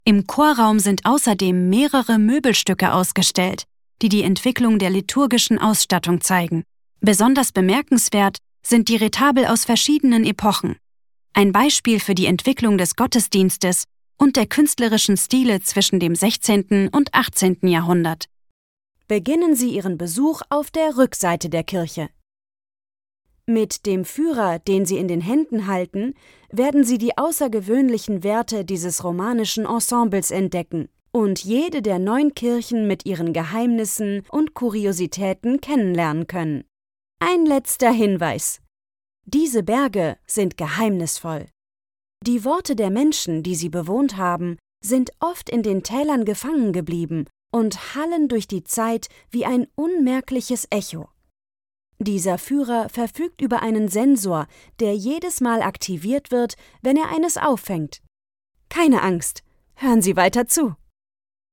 Junge, Natürlich, Verspielt, Zugänglich, Freundlich
Audioguide